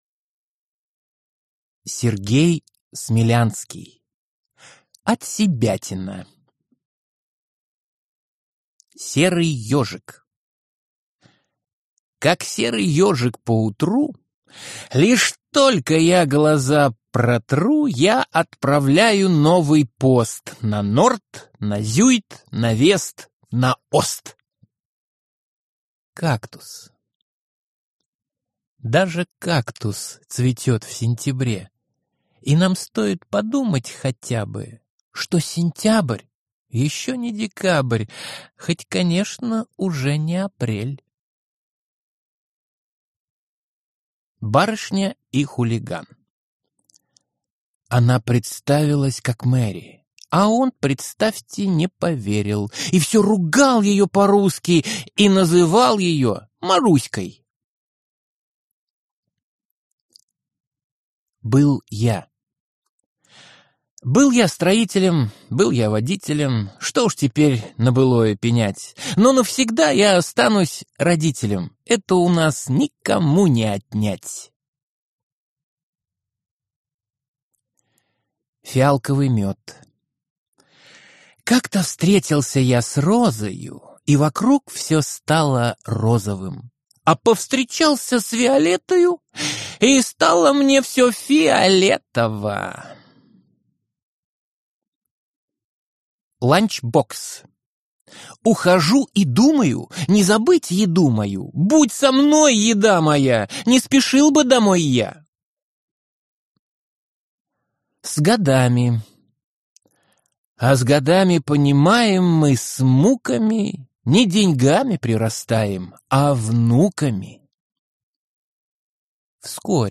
Аудиокнига Отсебятина | Библиотека аудиокниг
Прослушать и бесплатно скачать фрагмент аудиокниги